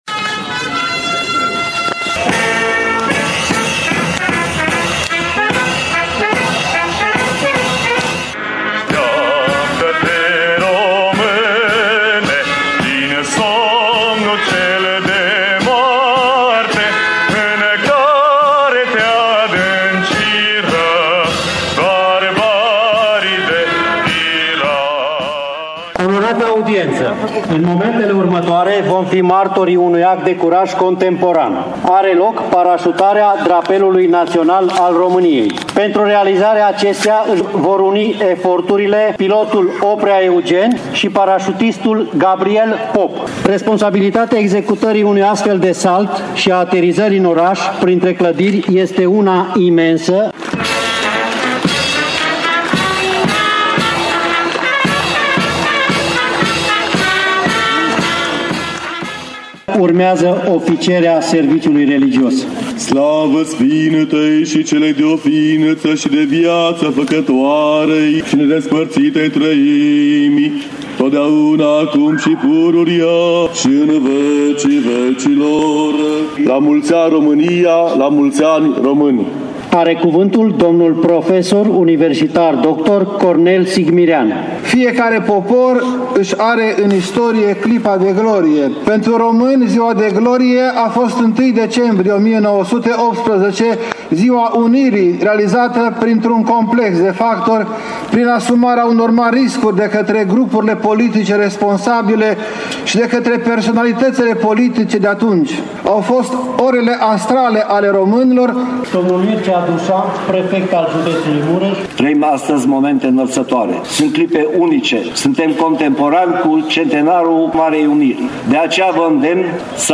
Ceremonia militară a fost completată de forțele aeriene, respectiv de două elicoptere IAR PUMA 330 și 6 MIG-uri 21 de la Baza Aeriană de la Câmpia Turzii: